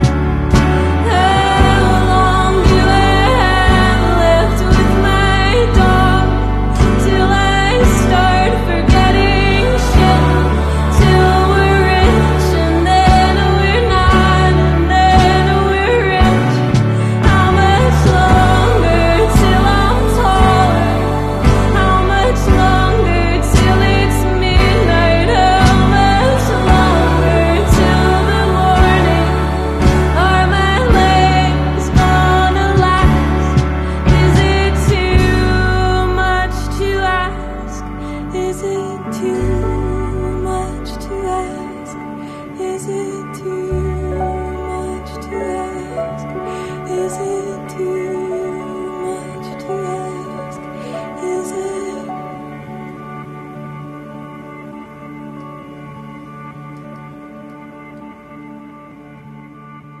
gulp.